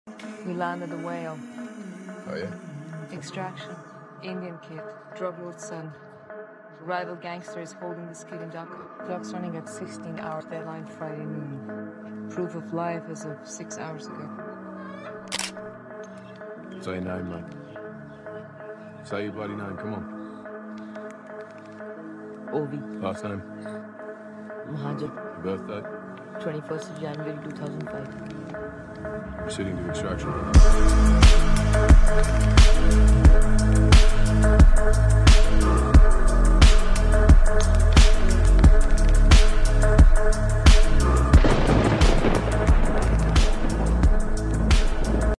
(slowed+reverb)